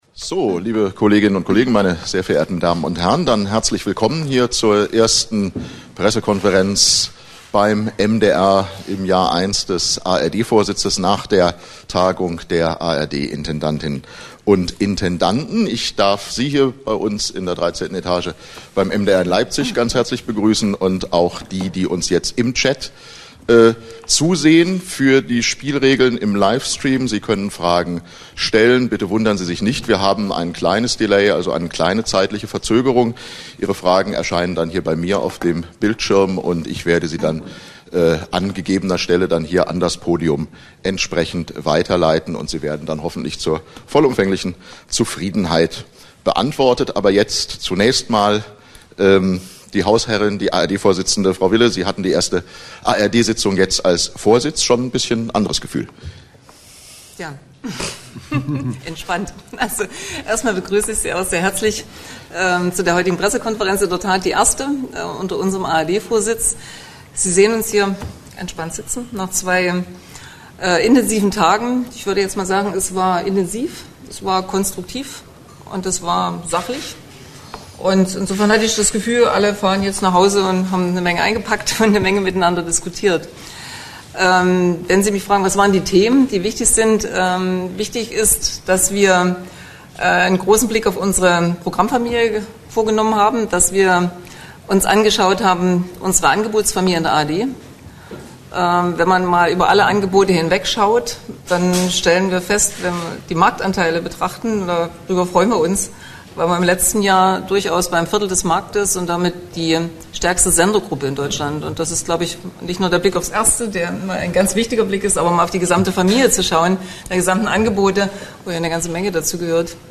ARD-Pressekonferenz Leipzig
Was: ARD-Pressekonferenz im Anschluss an die Sitzung der Intendantinnen und Intendanten
Wo: Leipzig, mdr-Hochhaus, 13. Etage